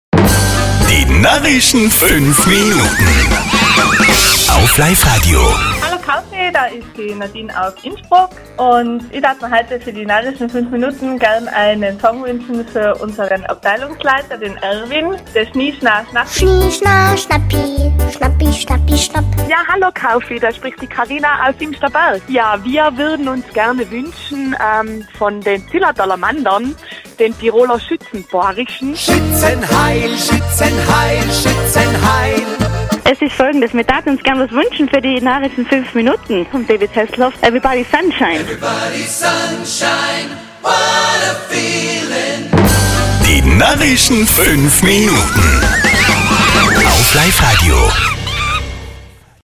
Wir sind wieder in Tirol unterwegs und befragen die TirolerInnen über die verschiedensten Themen!